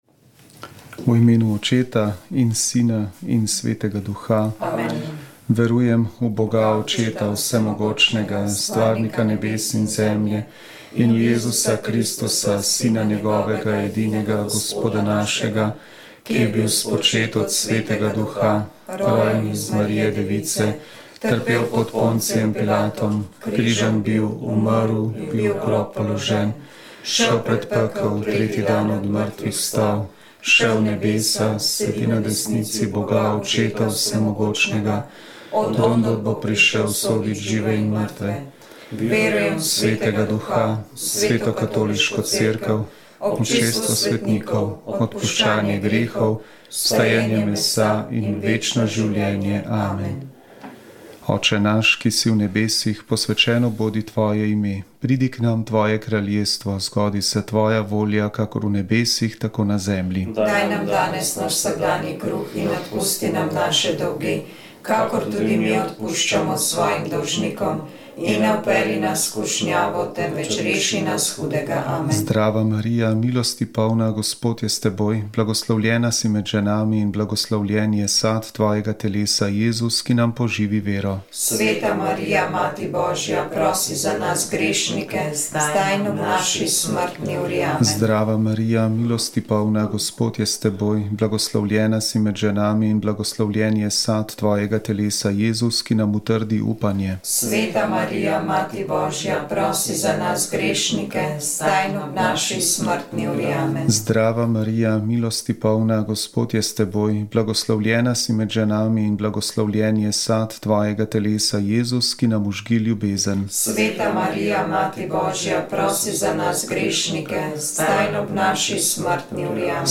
V našem studiu je bil predsednik Nove Slovenije Matej Tonin. V svetovalnici smo odprli telefone in dali prostor našim poslušalcem, da zastavijo vprašanja našemu gostu.